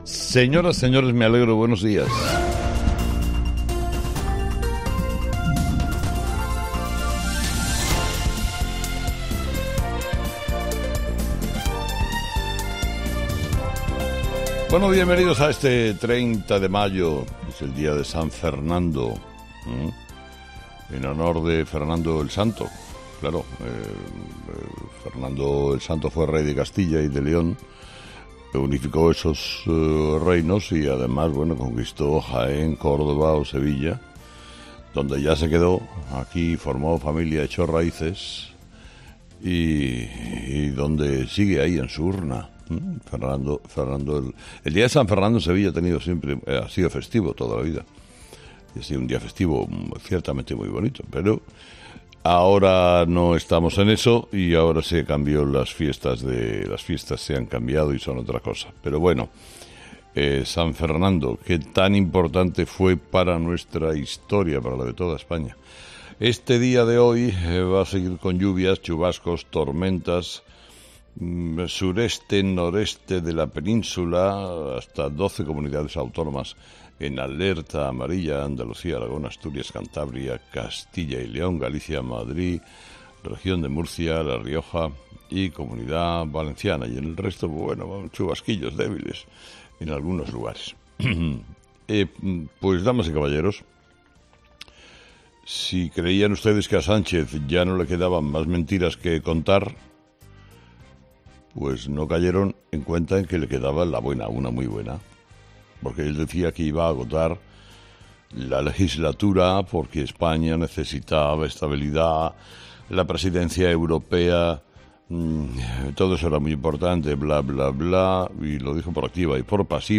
Escucha el análisis de Carlos Herrera a las 06:00 horas en Herrera en COPE este martes 30 de mayo de 2023
Carlos Herrera, director y presentador de 'Herrera en COPE', comienza el programa de este martes analizando las principales claves de la jornada, que pasan, entre otros asuntos, por la decisión de Pedro Sánchez de adelantar las elecciones generales de diciembre al 23 de julio.